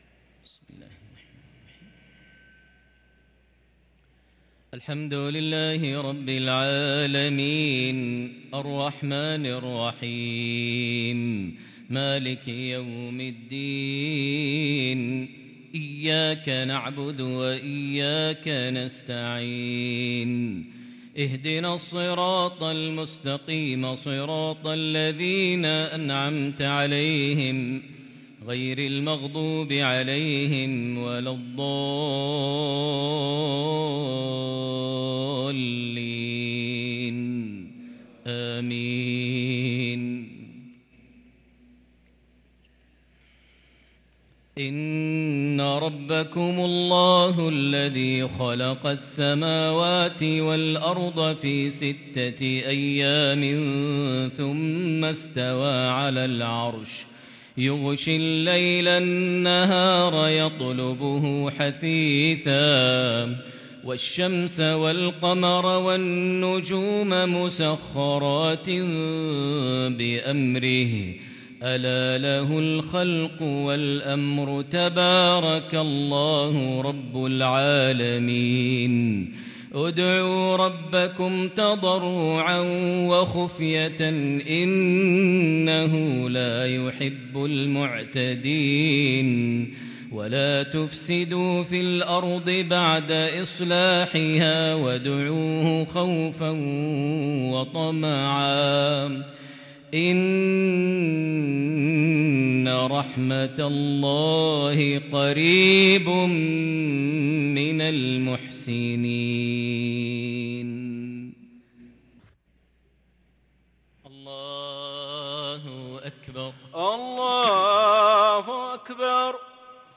صلاة المغرب للقارئ ماهر المعيقلي 18 ربيع الآخر 1444 هـ
تِلَاوَات الْحَرَمَيْن .